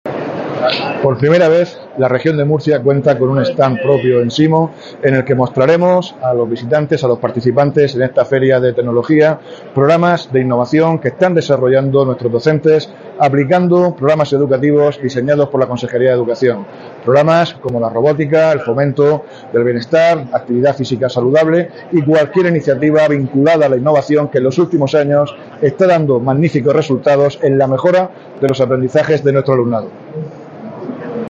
Víctor Marín, consejero de Educación, Formación Profesional y Empleo